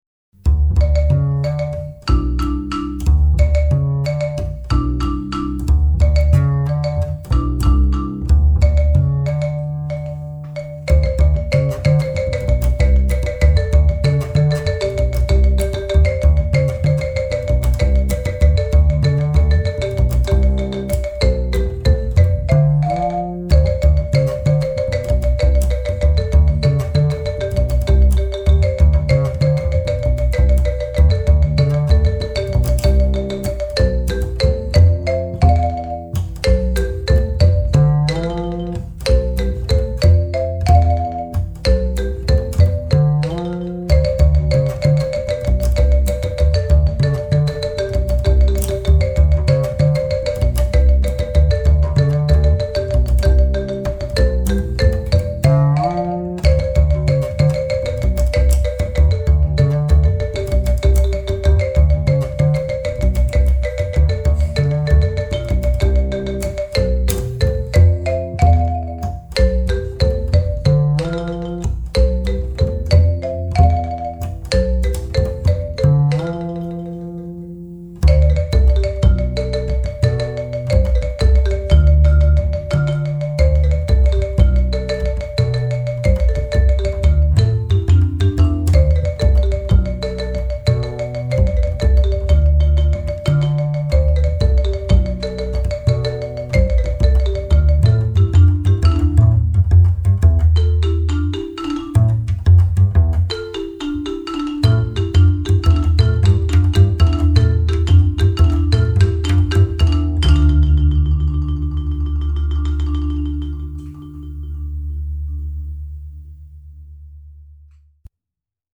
akustik